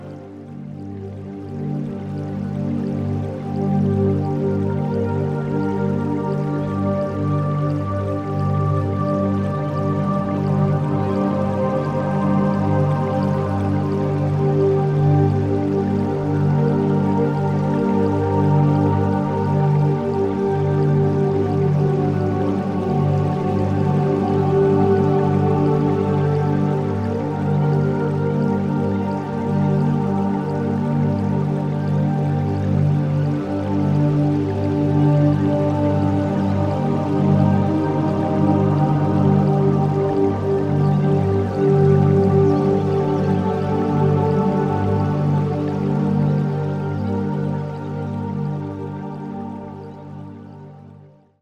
Rebalancing and uplifting.